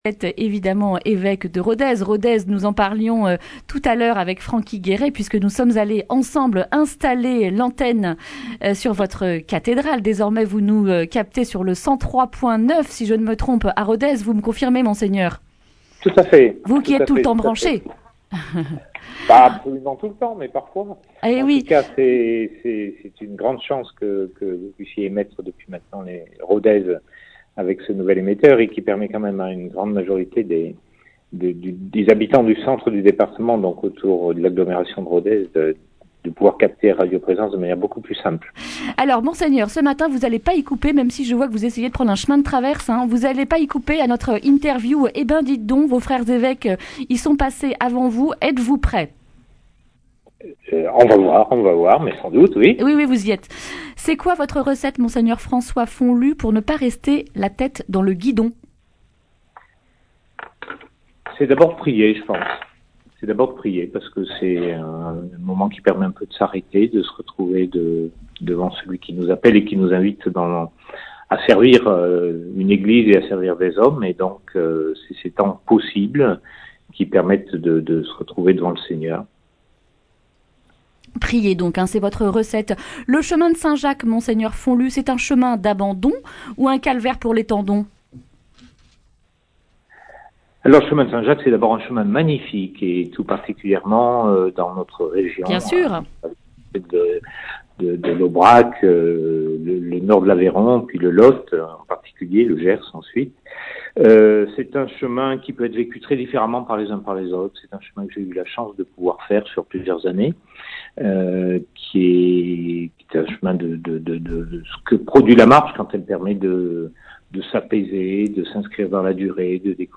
vendredi 22 novembre 2019 Le grand entretien Durée 10 min